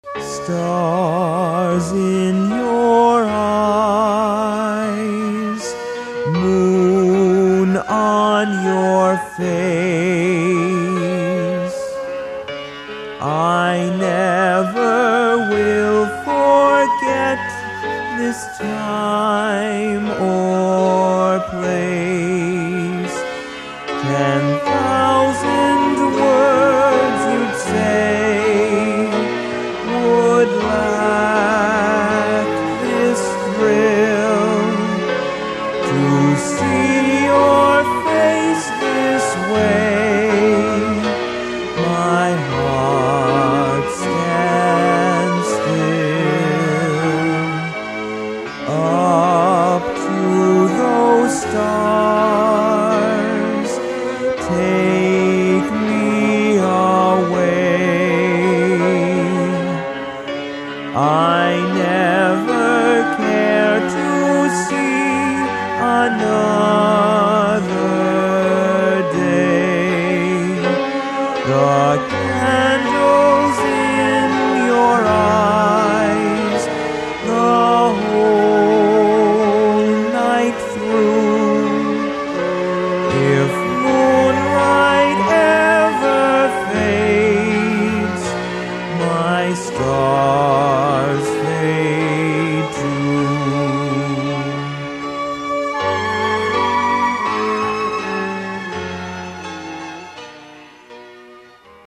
Seoul Olympics Arts Festival '88